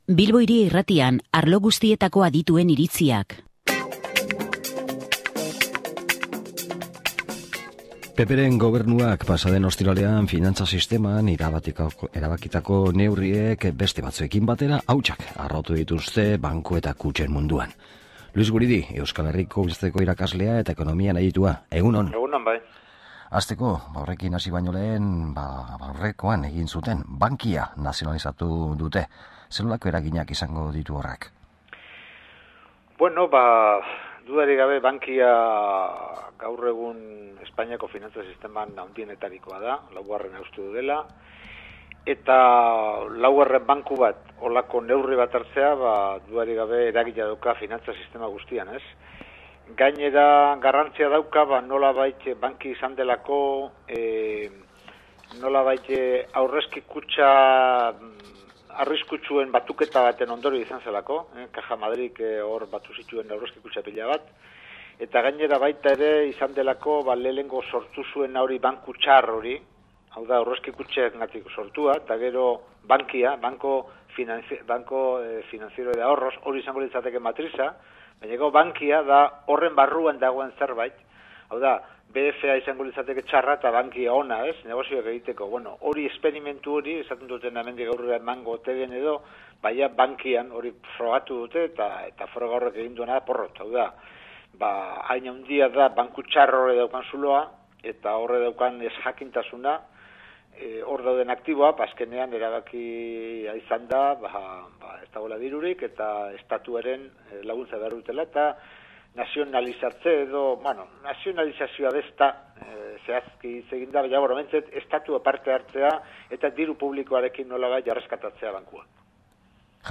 SOLASLALDIA